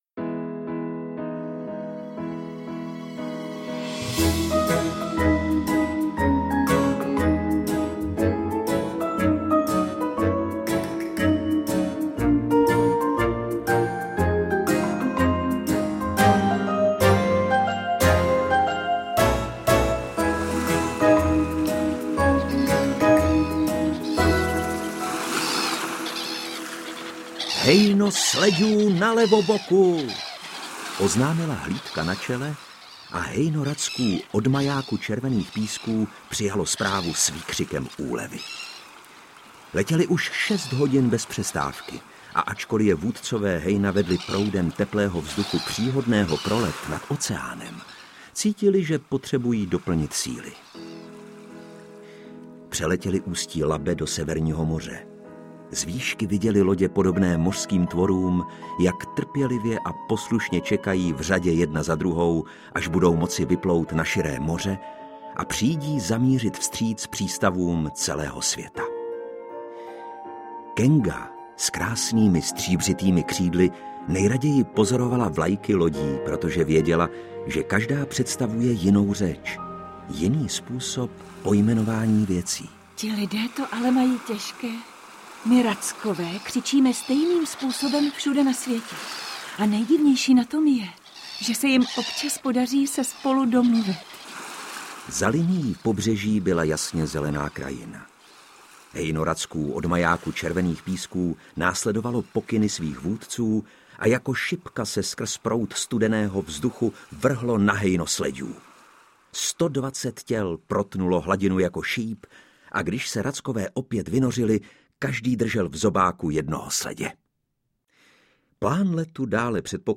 Audioverze světově proslulého příběhu o dobrodružství kočky a racka.